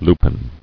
[lu·pine]